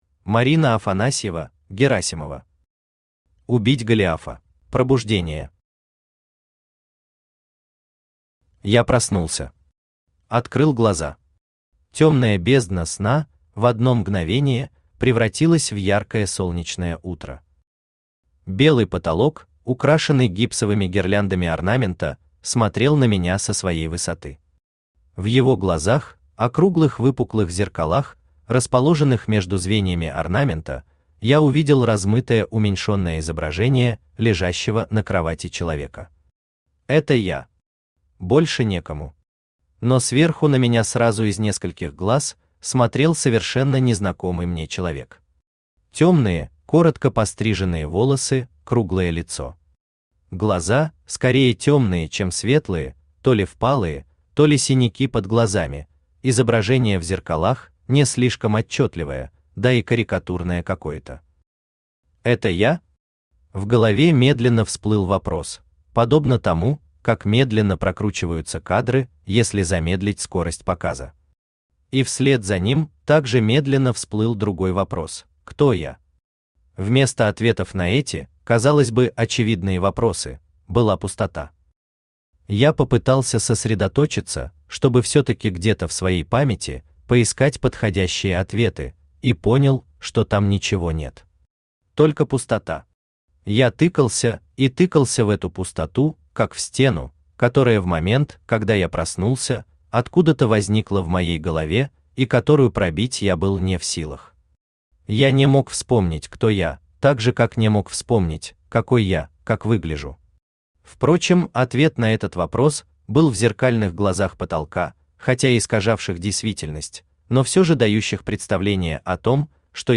Аудиокнига Убить Голиафа | Библиотека аудиокниг
Aудиокнига Убить Голиафа Автор Марина Валерьевна Афанасьева (Герасимова) Читает аудиокнигу Авточтец ЛитРес.